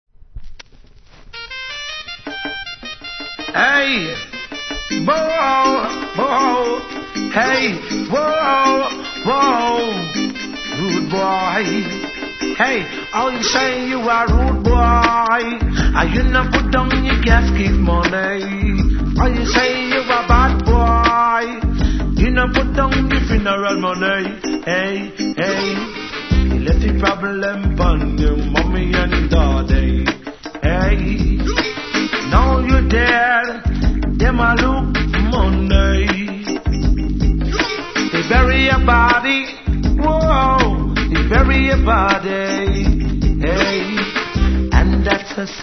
Please post only reggae discussions here